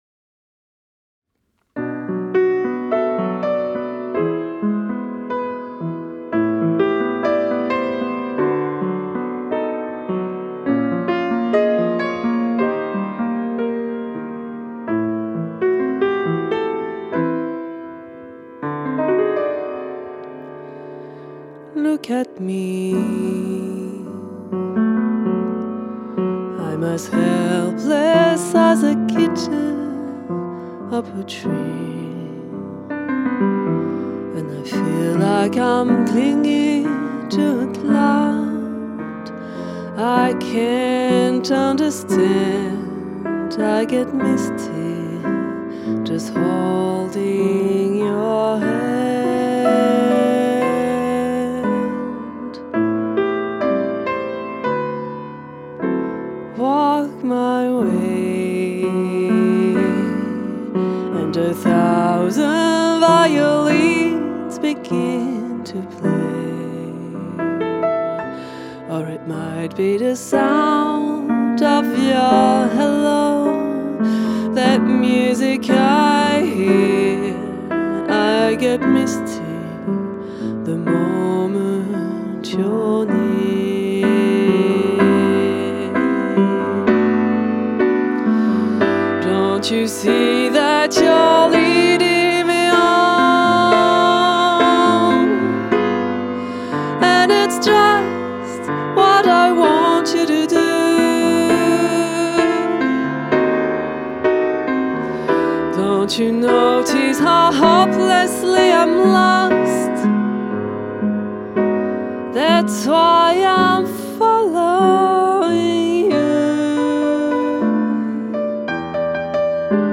Enregistrement Piano/Chant/Sax 2017-2018
Le duo piano/chant et saxophone tenor